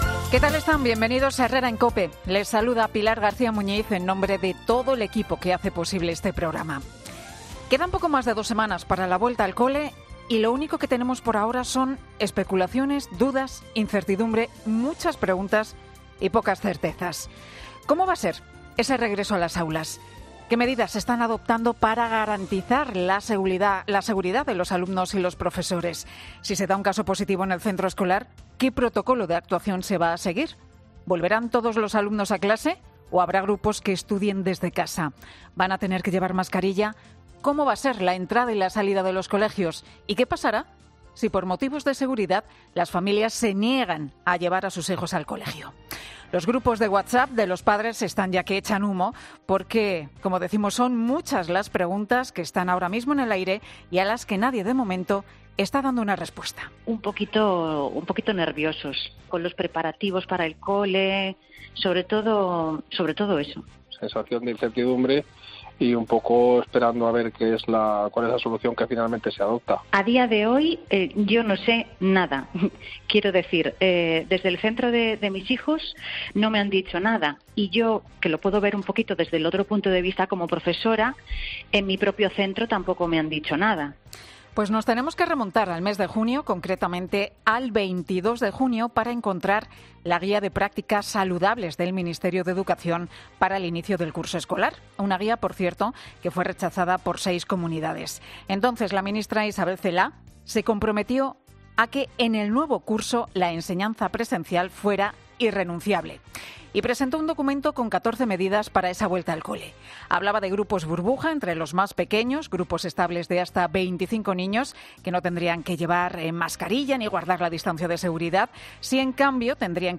AUDIO: La incertidumbre ante la inminente vuelta al cole, entre los temas de la comunicadora de 'Herrera en COPE', Pilar García Muñiz en su monólogo...